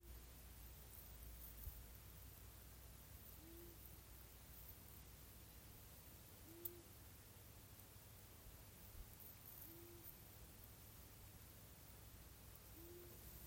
Ausainā pūce, Asio otus
StatussDzied ligzdošanai piemērotā biotopā (D)